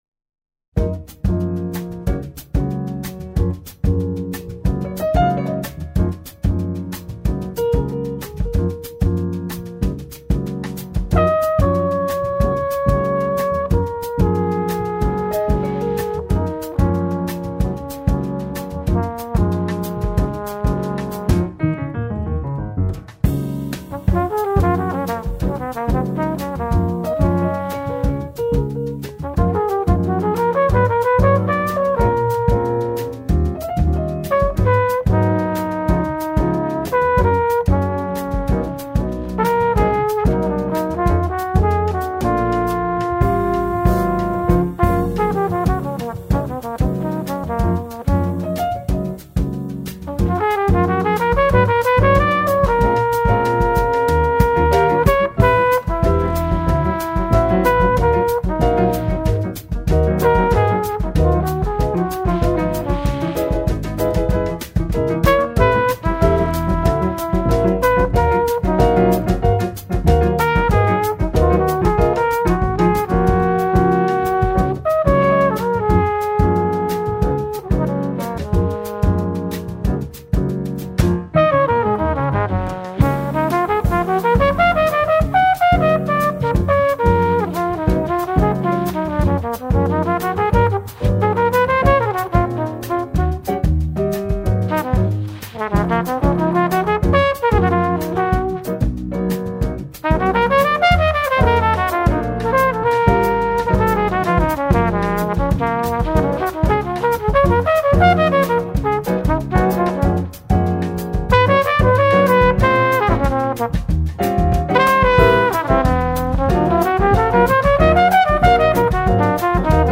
Brazil / jazz.
trumpeter
he explores glowing Brazilian moods.